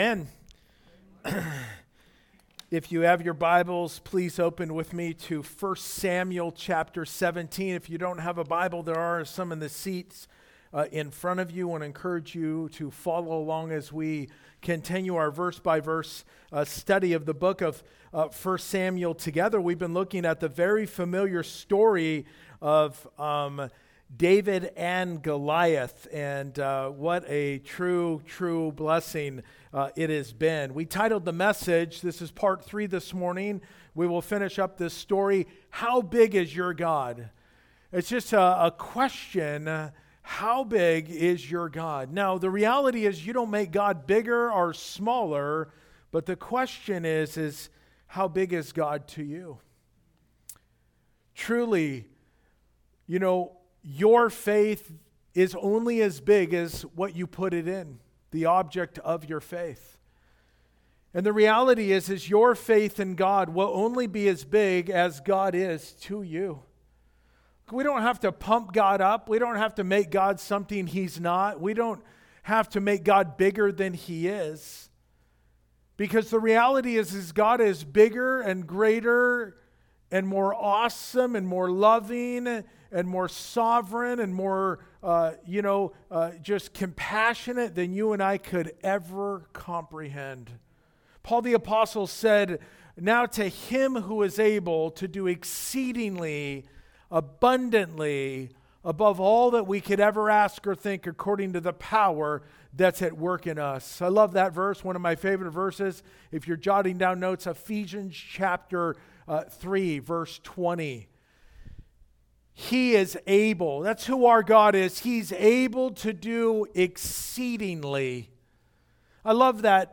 How+Big+is+your+God+pt+3+2nd+service.mp3